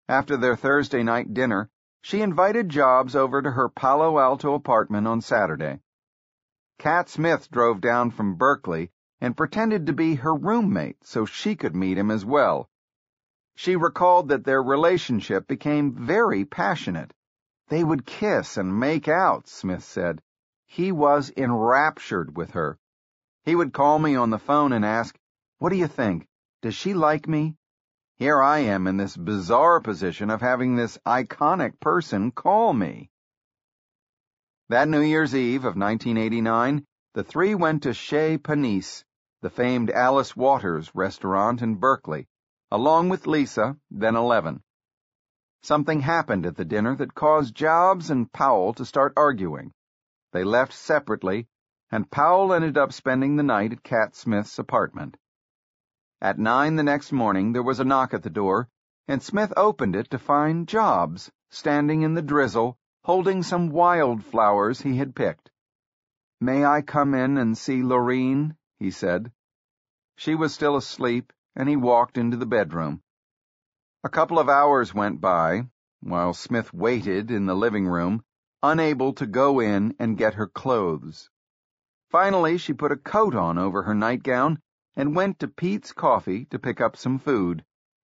本栏目纯正的英语发音，以及完整的传记内容，详细描述了乔布斯的一生，是学习英语的必备材料。